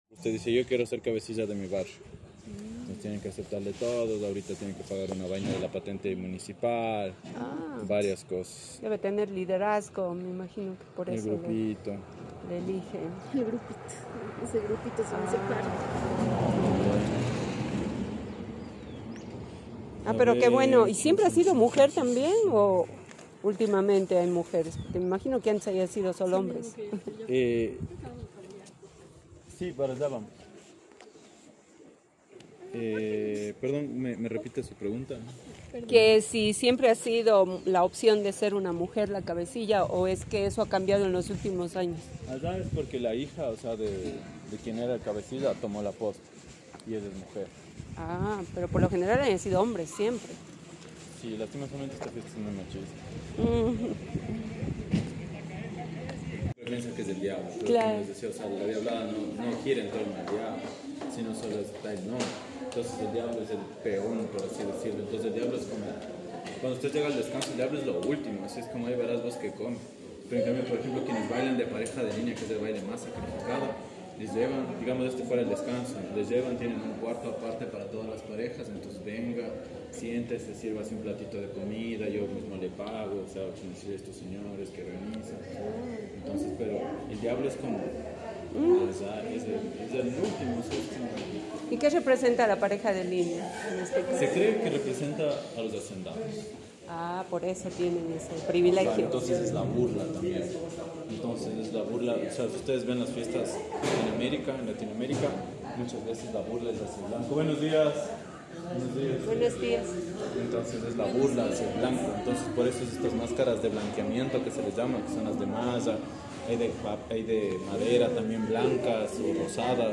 Repositorio Digital CIDAP: Entrevista a personajes de la tradicional Diablada de Píllaro
ENTREVISTA A PERSONAJES DE LA DIABLADA DE PÍLLARO.m4a